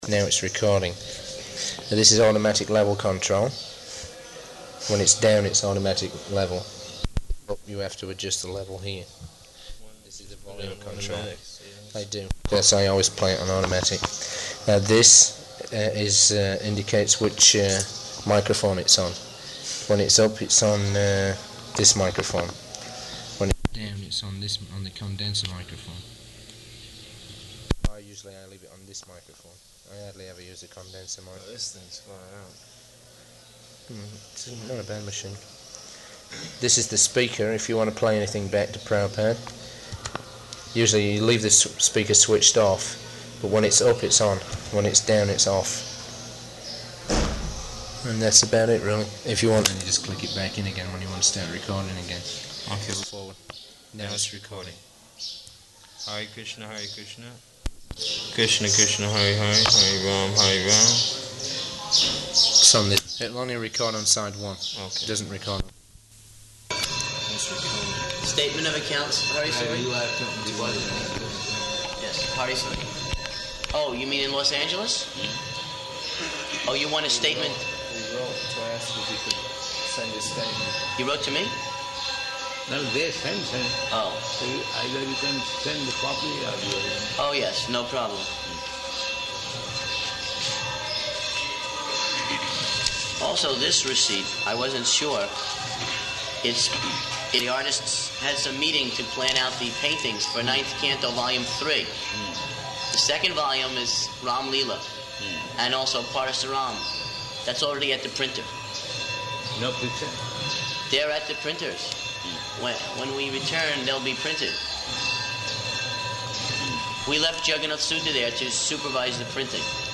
Room Conversation With Artists and About BTG